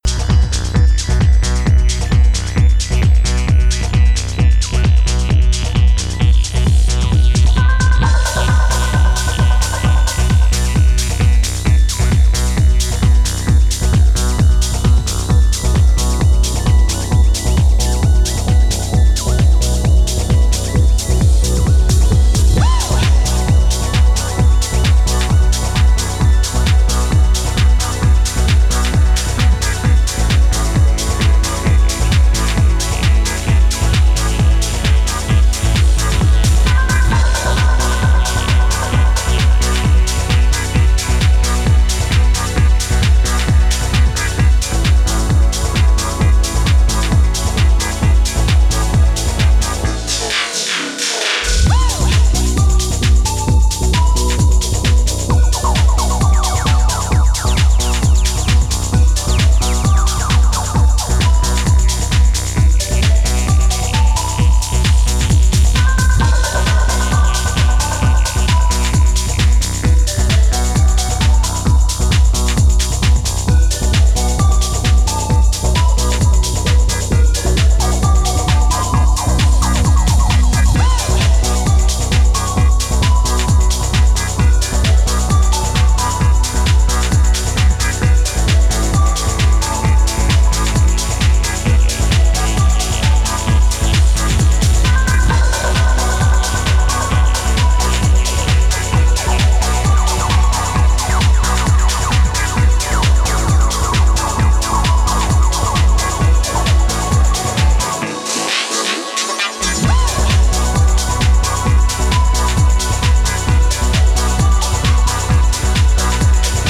hazy, blissed-out cloudscape